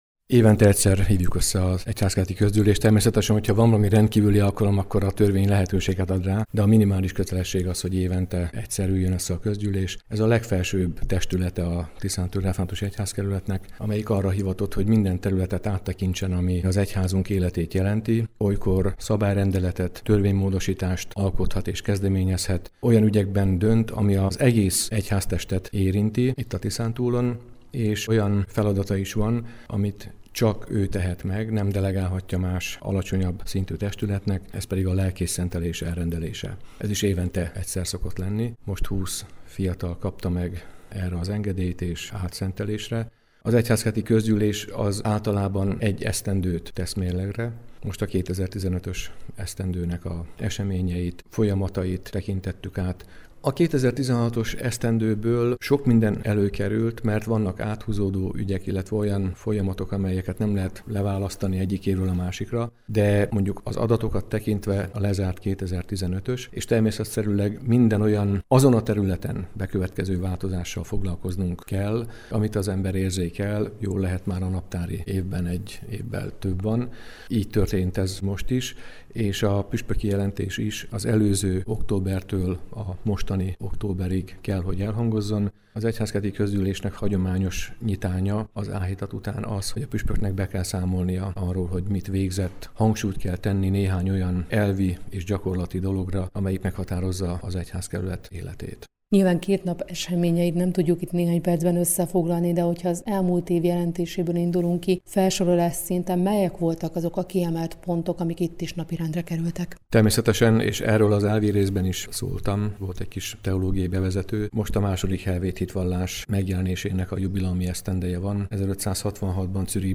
egyhazker-kozgyul-puspok.mp3